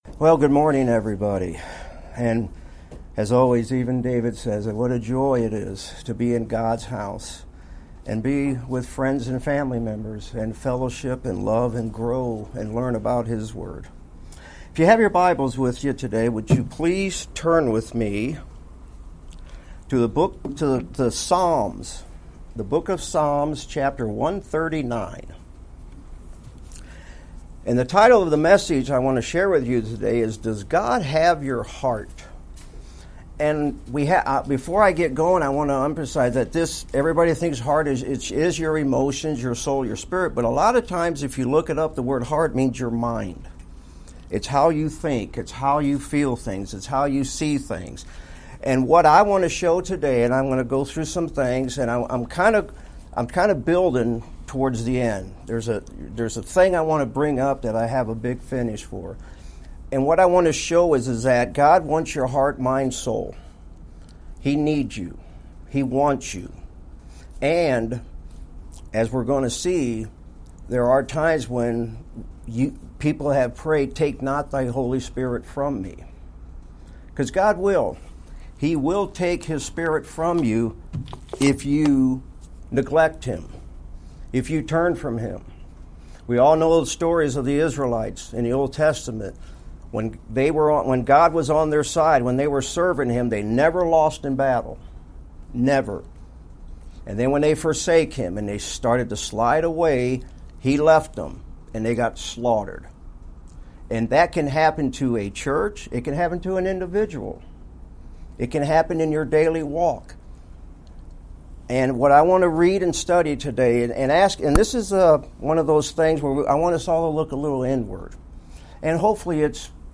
2025 Sermons